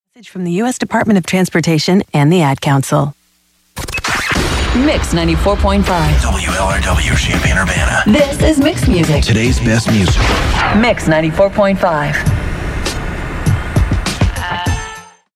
WLRW Top of the Hour Audio: